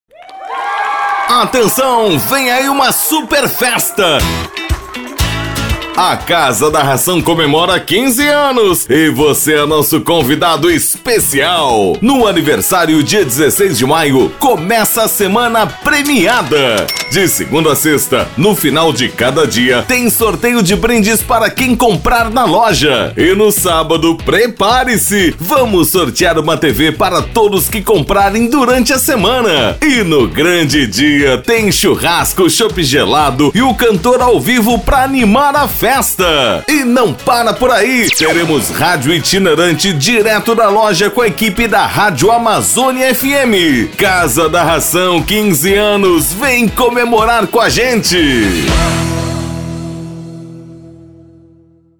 SPOT - AGROPECUARIA:
Spot Comercial
Impacto
Animada